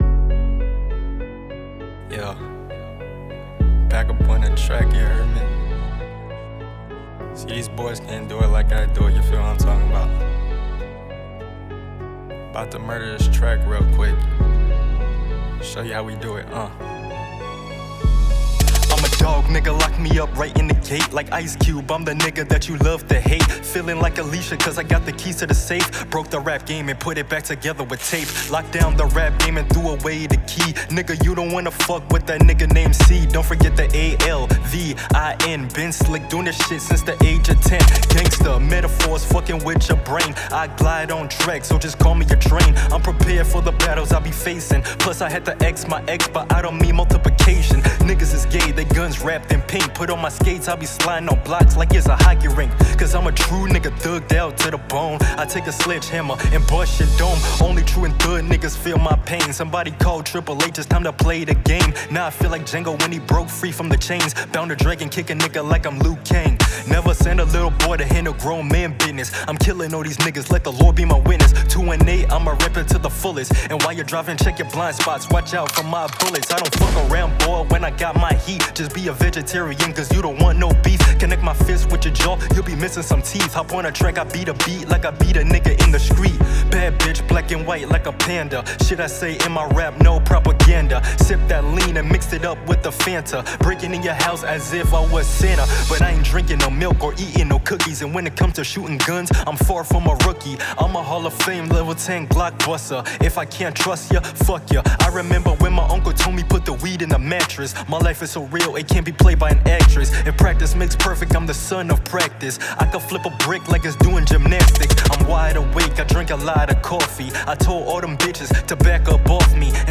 Real client mixes
Hip hop / Rap Mix & Master (SpacePod Exclusive)